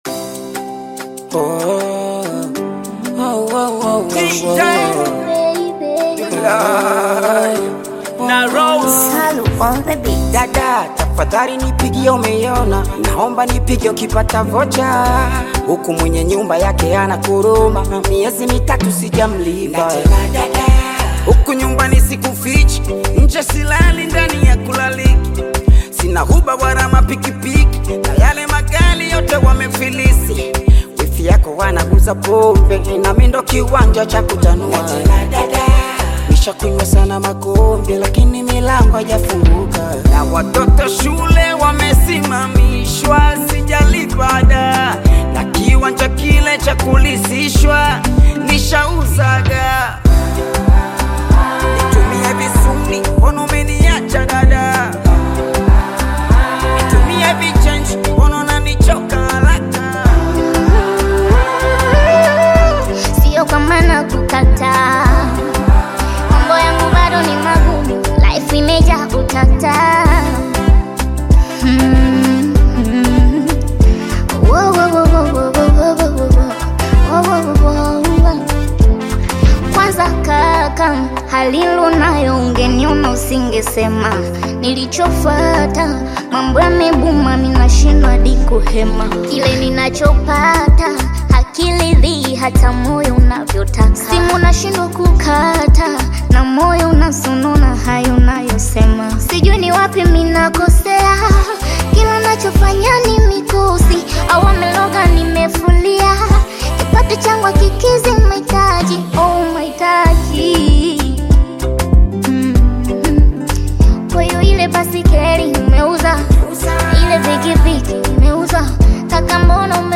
Tanzanian veteran bongo flava artist
African Music